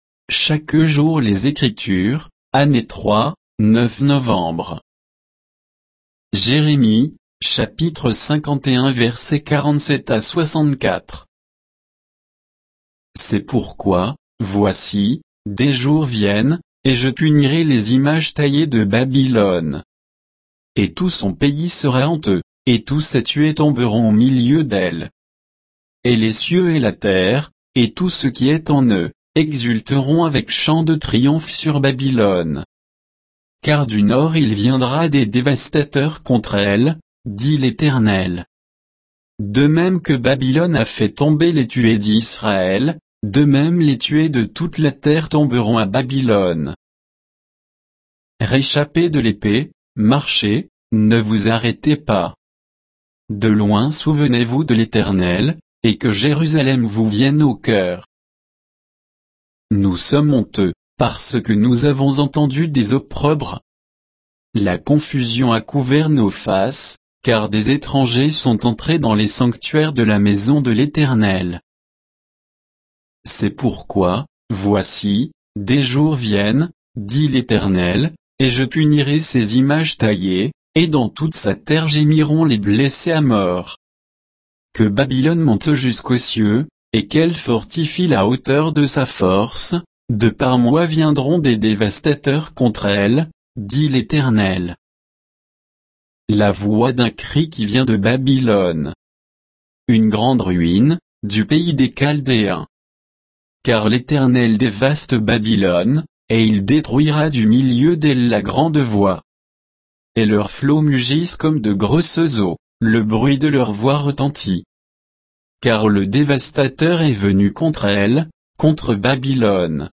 Méditation quoditienne de Chaque jour les Écritures sur Jérémie 51